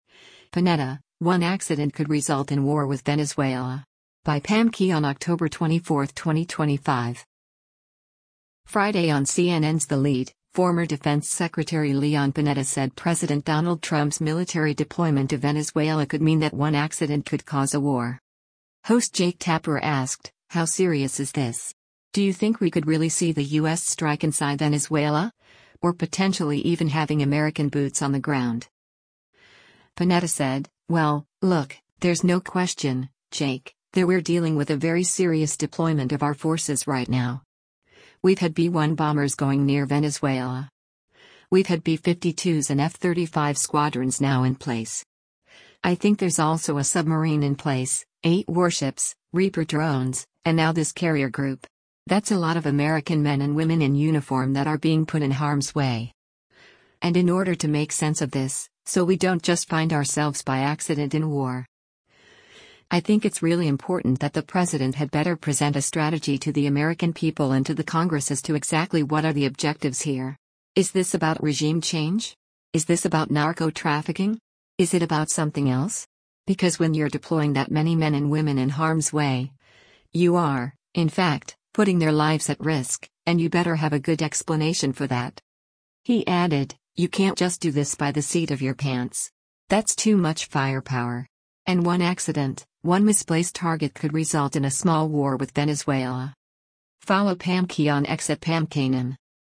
Friday on CNN’s “The Lead,” former Defense Secretary Leon Panetta said President Donald Trump’s military deployment to Venezuela could mean that one accident could cause a war.